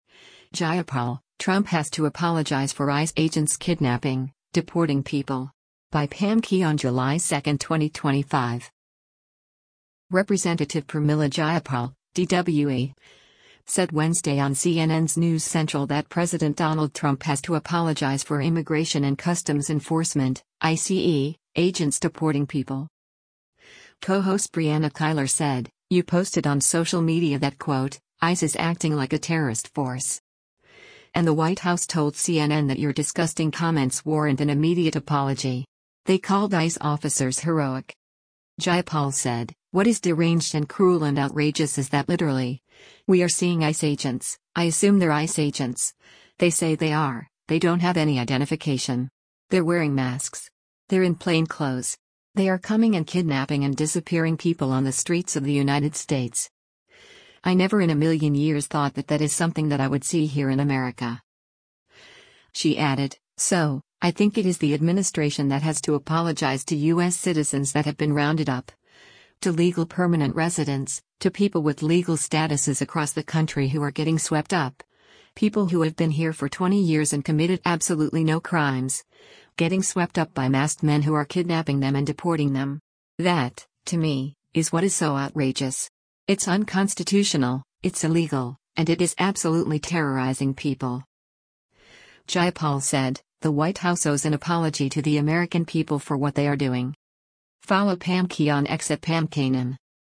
Representative Pramila Jayapal (D-WA) said Wednesday on CNN’s “News Central” that President Donald Trump “has to apologize” for Immigration and Customs Enforcement (ICE) agents deporting people.